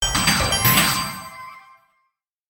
10183 level up shock ding
advance alert bonus ding game level score shock sound effect free sound royalty free Gaming